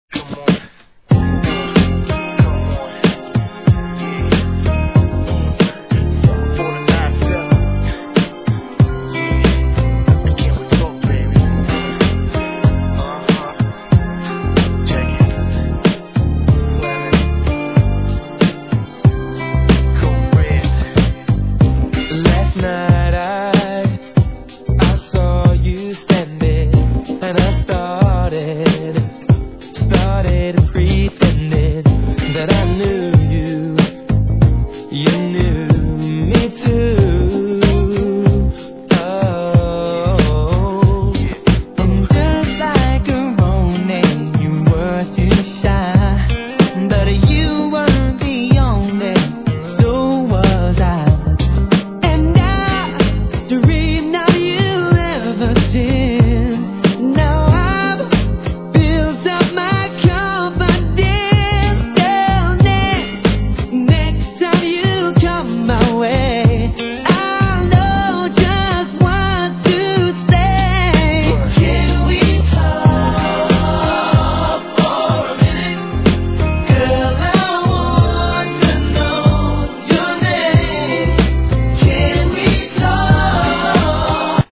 HIP HOP/R&B
CLUB MIX